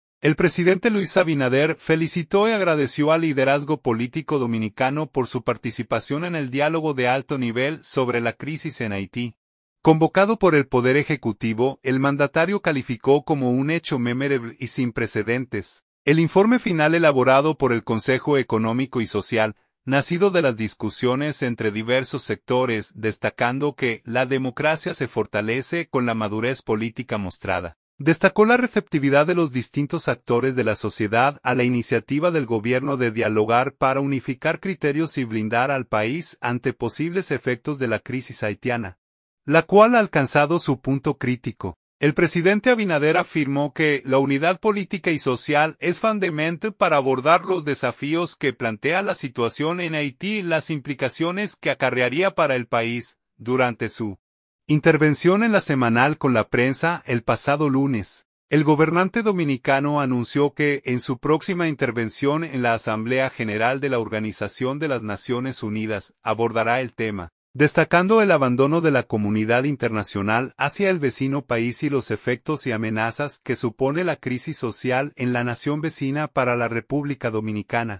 Durante su intervención en LA Semanal con la prensa, el pasado lunes, el gobernante dominicano anunció que en su próxima intervención en la Asamblea General de la Organización de las Naciones Unidas, abordará el tema, destacando el abandono de la comunidad internacional hacia el vecino país y los efectos y amenazas que supone la crisis social en la nación vecina para la República Dominicana.